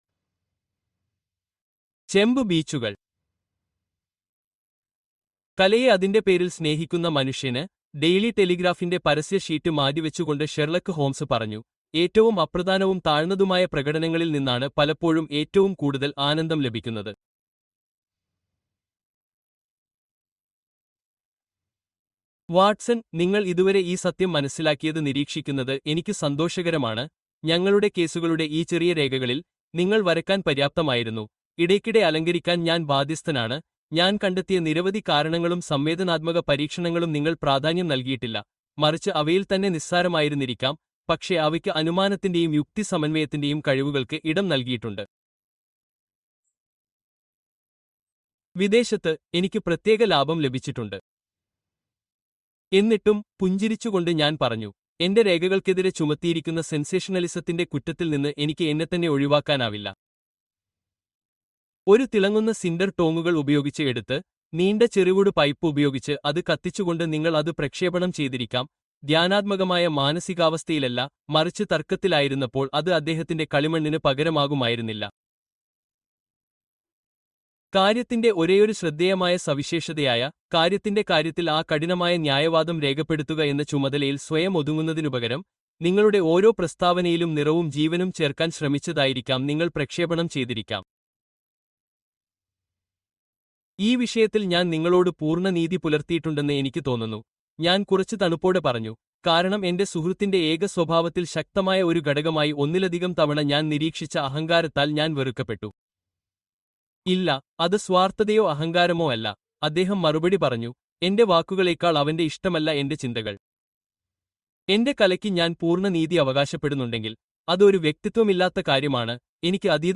The Problem of Thor Bridge: Deadly Secrets Revealed (Audiobook)